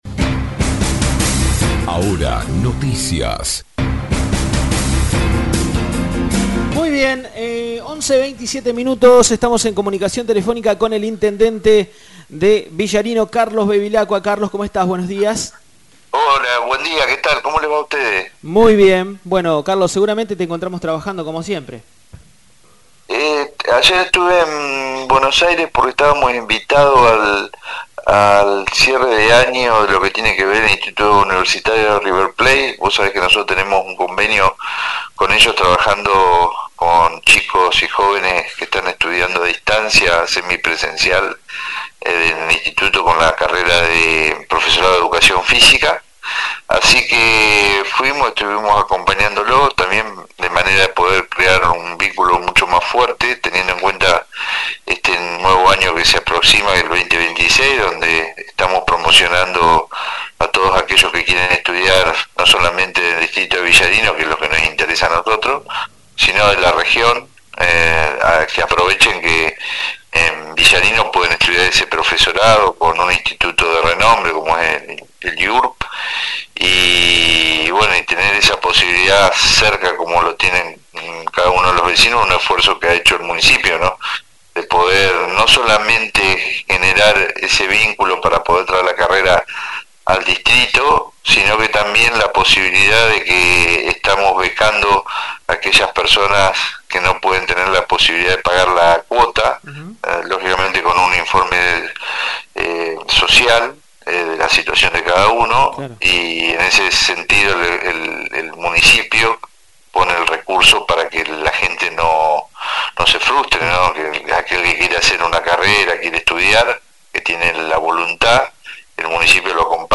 El Intendente Bevilacqua dejó su saludo por los 112° de Pedro Luro y destacó avances en gestión